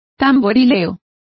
Complete with pronunciation of the translation of drumming.